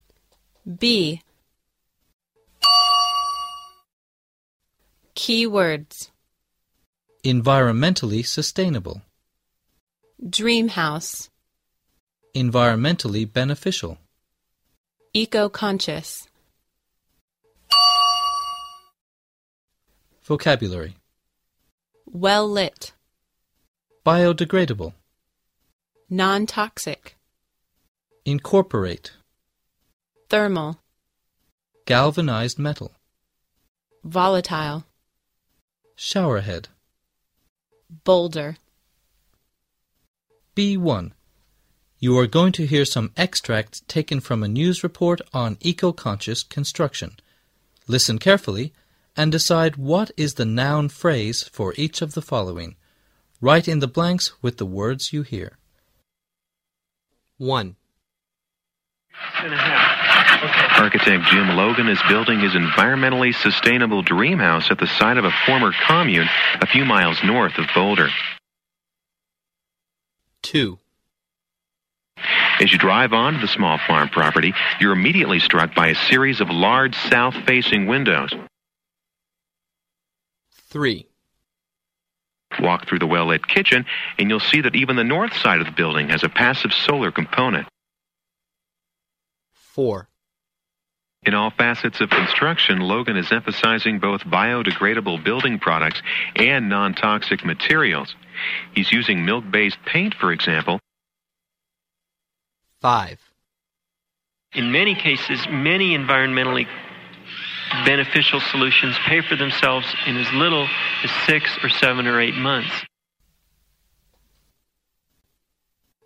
B1. You're going to hear some extracts taken from a news report on eco-conscious construction.